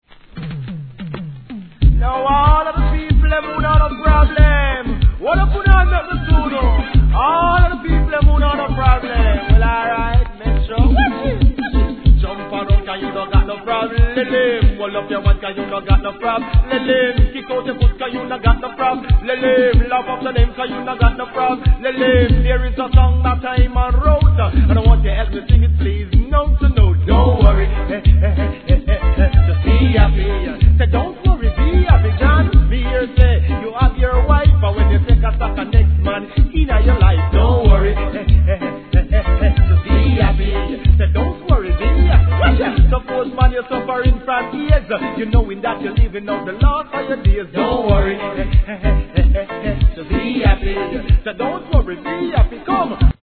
REGGAE
好RIDDIM、WICKED DeeJayスタイル!!